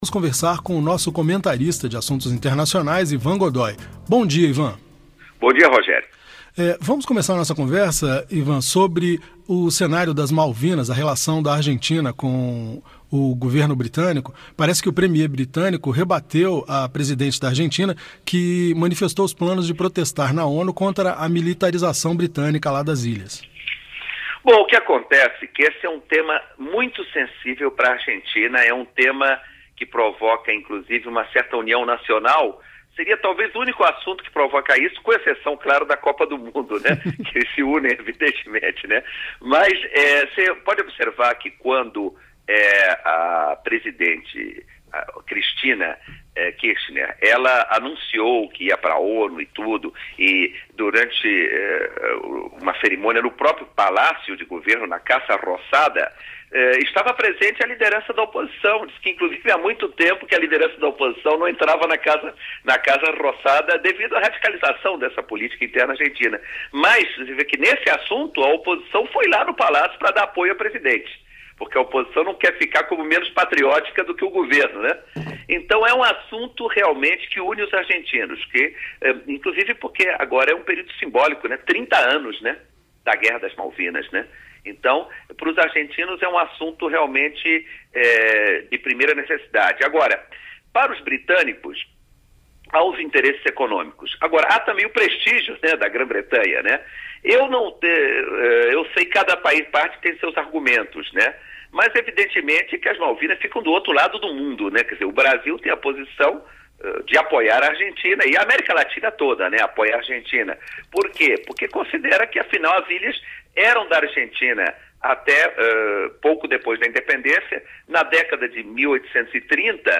Análise do comentarista internacional